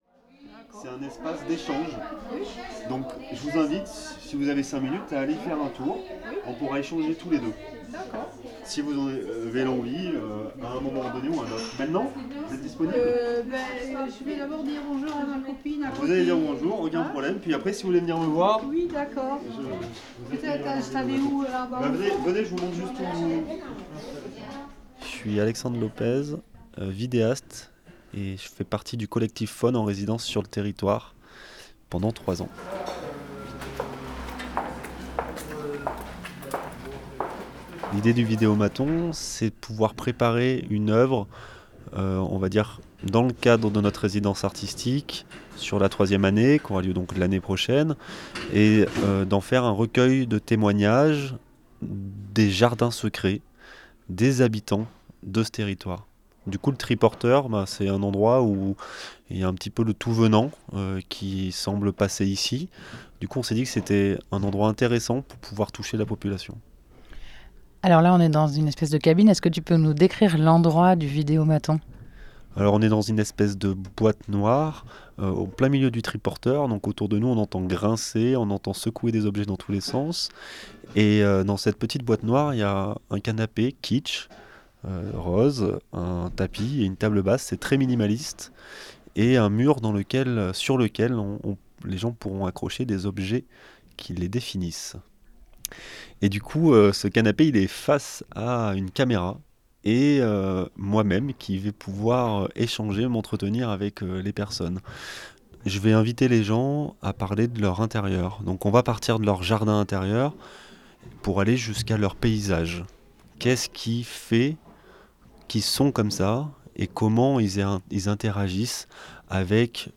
29 octobre 2019 12:28 | culture, Interview, reportage, territoire
Reportage-Vidéomaton.mp3